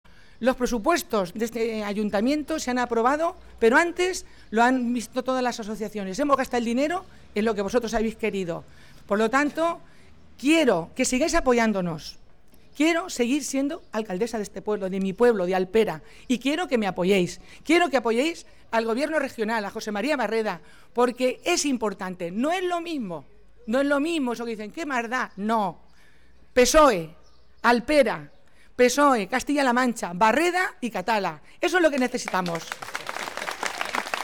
Alcaldesa_y_candidata_de_Alpera.mp3